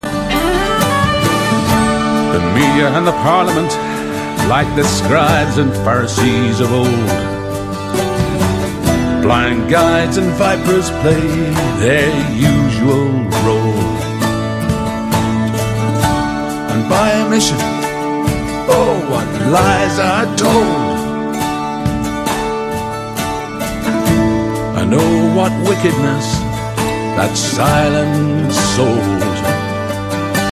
bass guitar
Uilleann pipes.